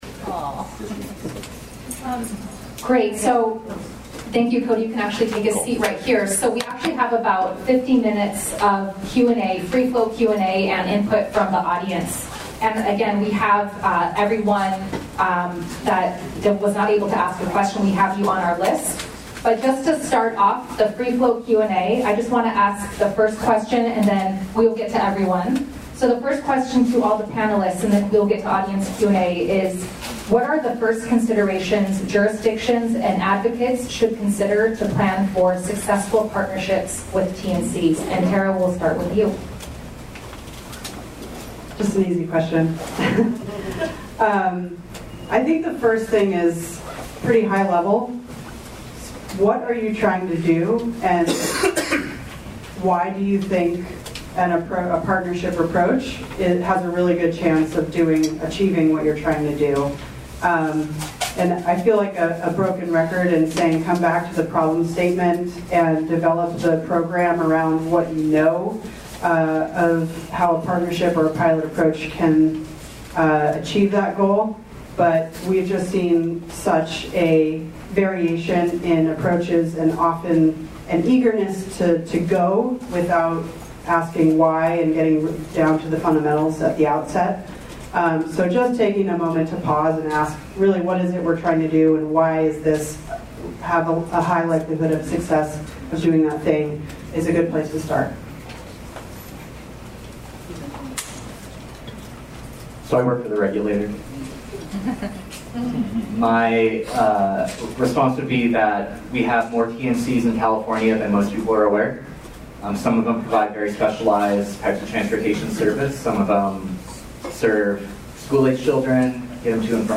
Location Alameda CTC Offices 1111 Broadway, Suite 800 Oakland, California, 94607